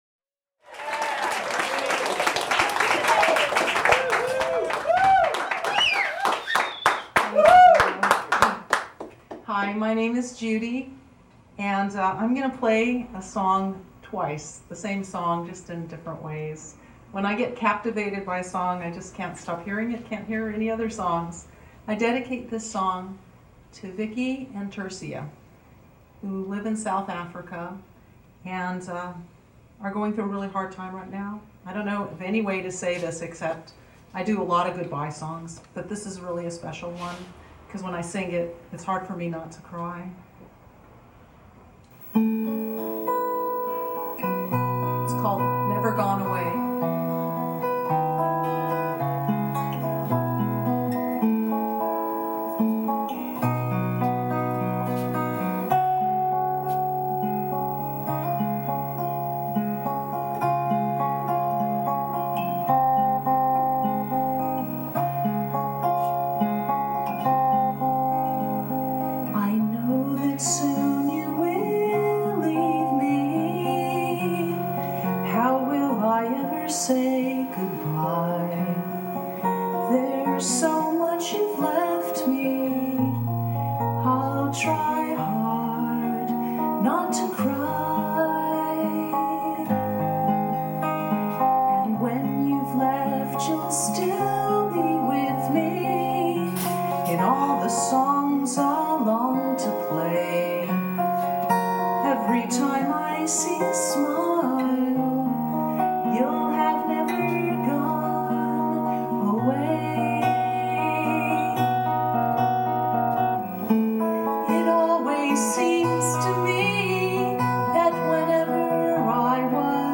Butterfly of grief 2The link below is for a recent performance of my song in progress:
NEVER GONE AWAY-ACOUSTIC PERFORMANCE